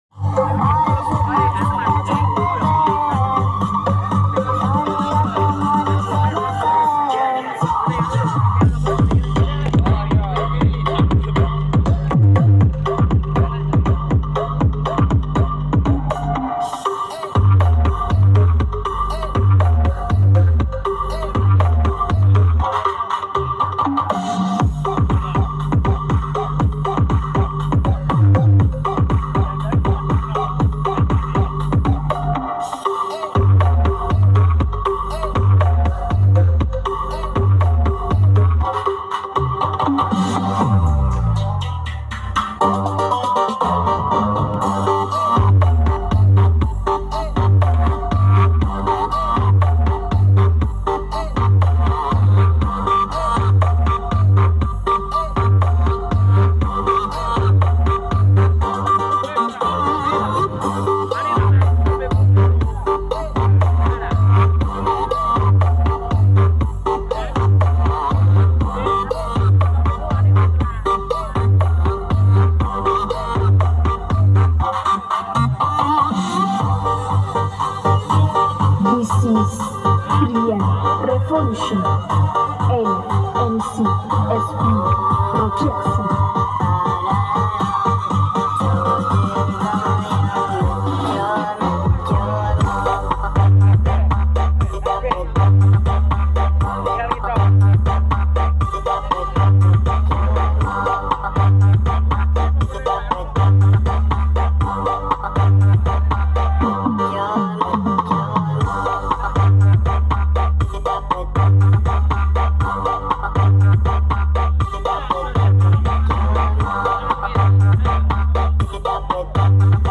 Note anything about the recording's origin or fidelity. lokasi Gajah-Demak-Jateng